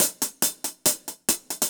Index of /musicradar/ultimate-hihat-samples/140bpm
UHH_AcoustiHatB_140-04.wav